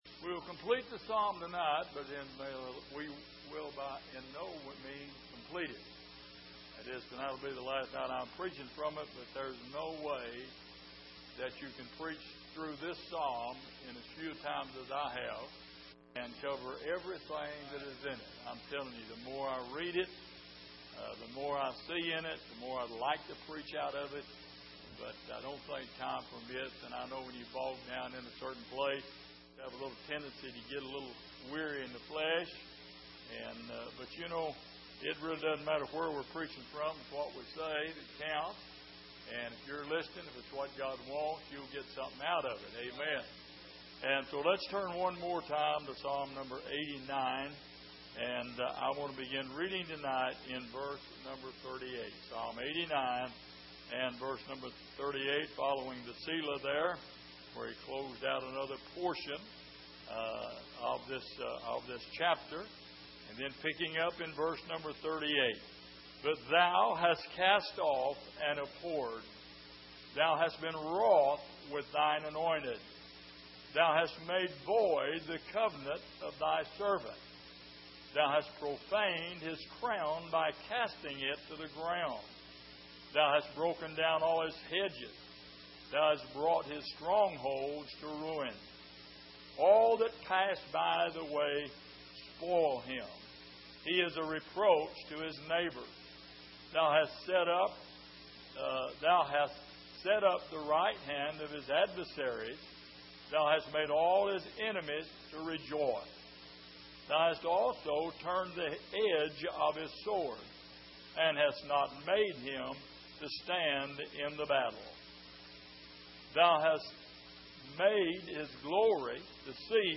Passage: Psalm 89:38-52 Service: Midweek